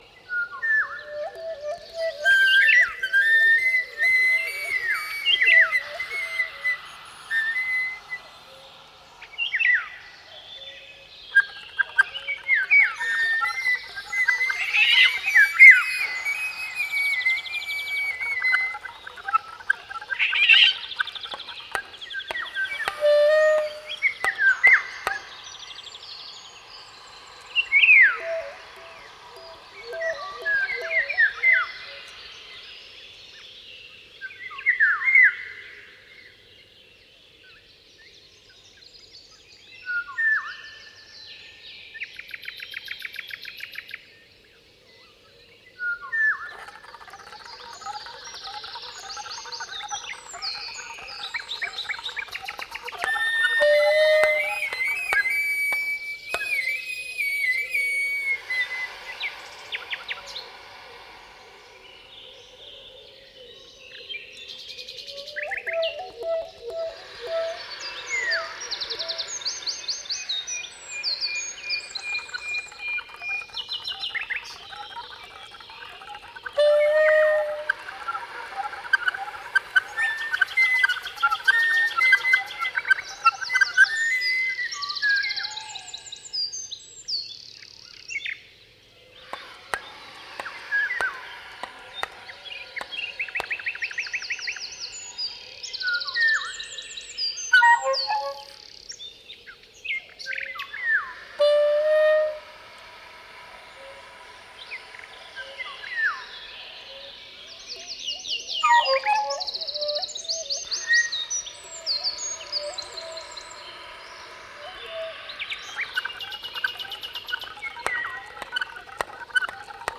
(sax soprano, sax tenore);